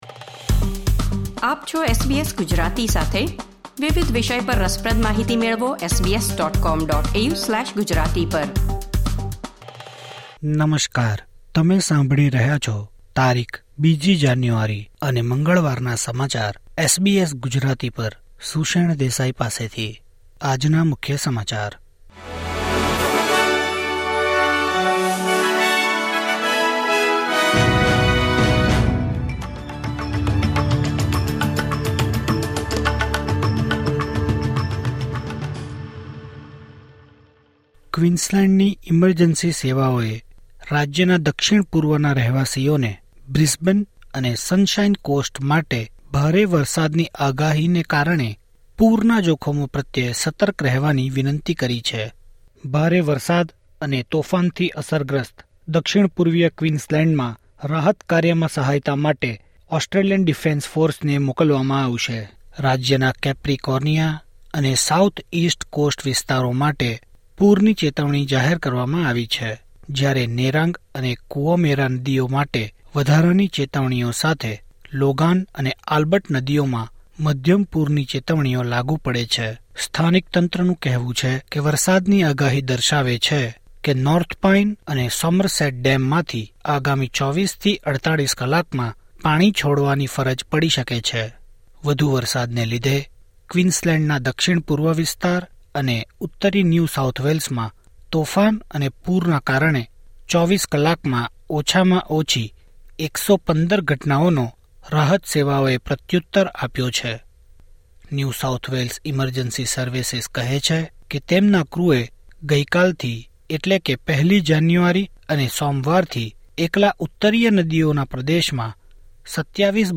SBS Gujarati News Bulletin 2 January 2024